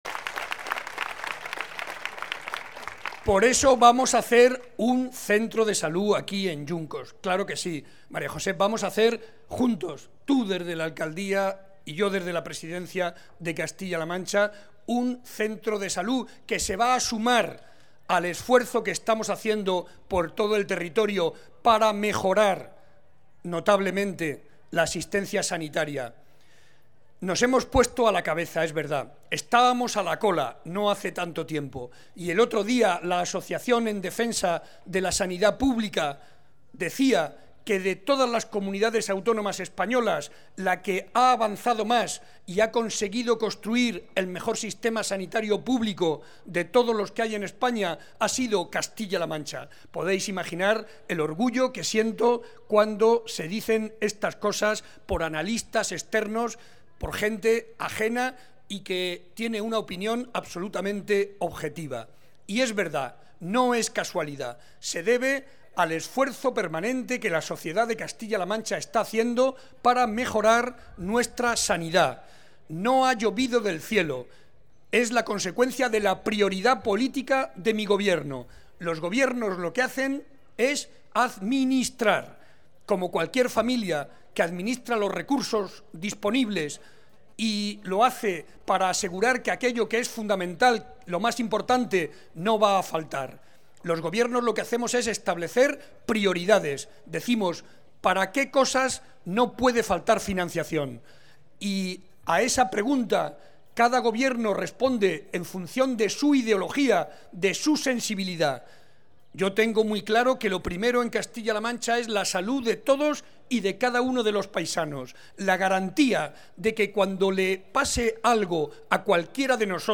Además ante más de 200 vecinos en la Plaza de la Villa, de Yuncos, en La Sagra toledana, se comprometió con un nuevo centro de salud para la localidad